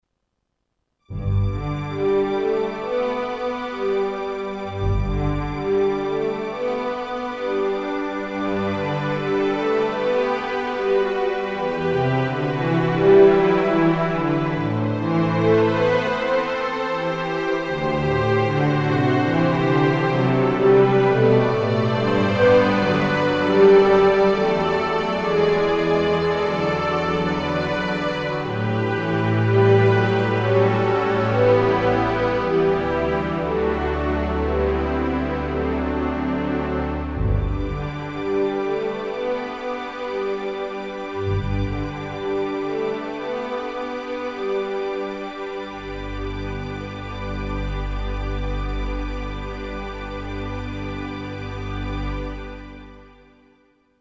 String ensemble Sound expansion
Rackmount unit based on orchestral strings samples from the nineties.
Internal waveforms are mainly strings, like cello, violin, ensemble but also various orchestral instruments e.g harp, timpani, harpsichord or tambourine.